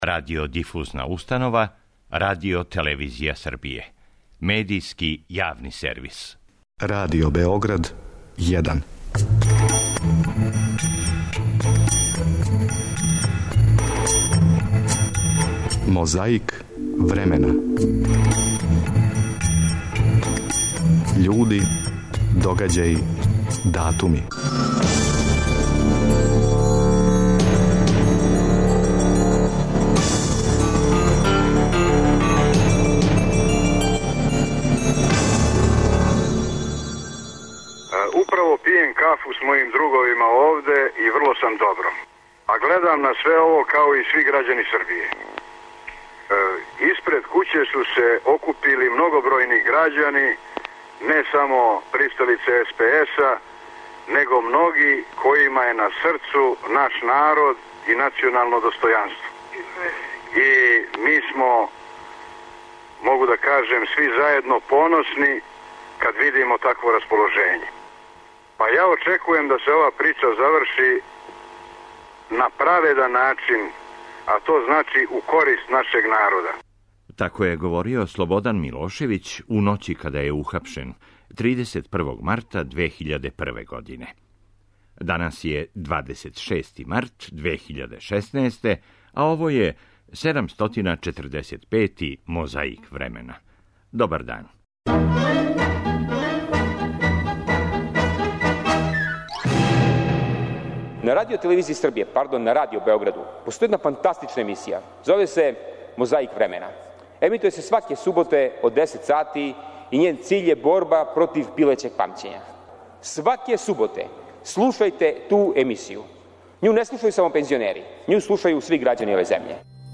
О овом догађају, десет година касније, говорио је Александар Лека Ранковић.
У емисији "Гост Другог програма" емитованој 30. марта 1985. године глумица Мира Бањац се сећала свог детињства и прве улоге.